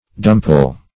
Search Result for " dumple" : The Collaborative International Dictionary of English v.0.48: Dumple \Dum"ple\, v. t. [See Dumpling .]